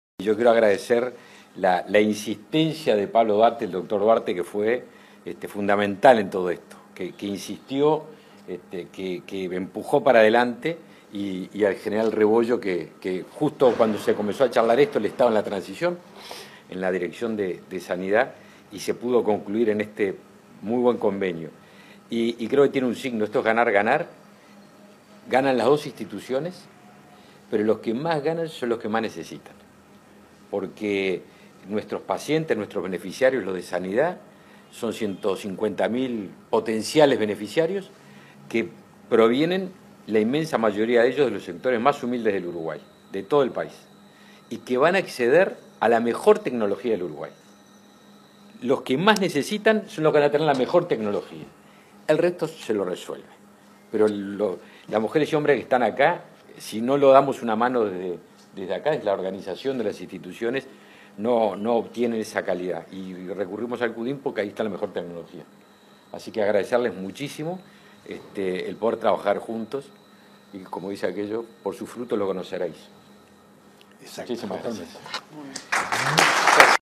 Palabras del ministro de Defensa Nacional, Javier García
Palabras del ministro de Defensa Nacional, Javier García 16/02/2023 Compartir Facebook X Copiar enlace WhatsApp LinkedIn En el marco del convenio entre el Centro Uruguayo de Imagenología Molecular (Cudim) y Sanidad Militar, el 16 de febrero, se expresó el ministro de Defensa Nacional, Javier García.